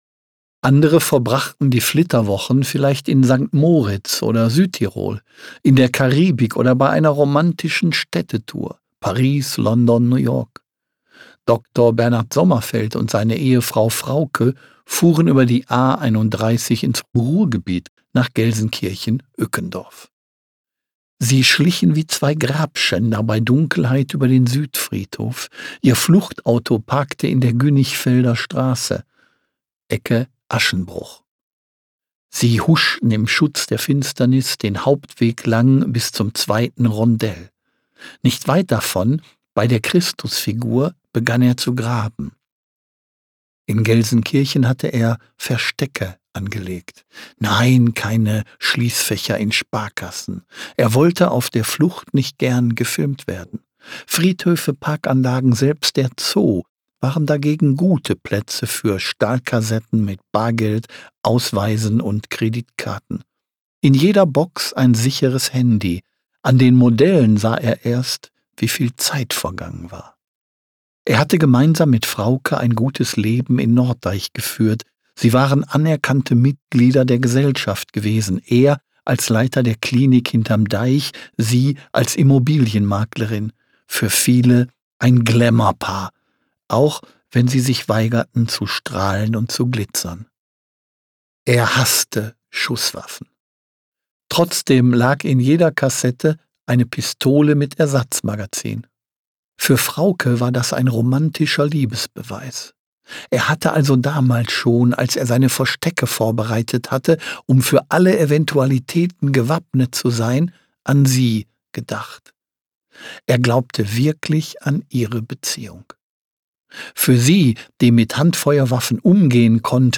Ein mörderisches Paar. Der Sturz ist das furiose Finale der Trilogie und wird von Bestsellerautor Klaus-Peter Wolf, der Nr. 1 in der Spannung, höchstpersönlich gesprochen.